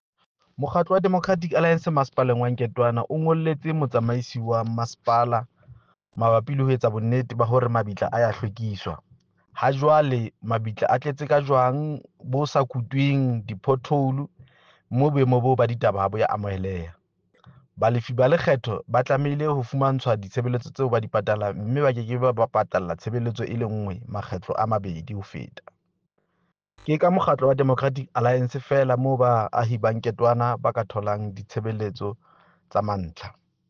Sesotho soundbite by Cllr Diphapang Mofokeng.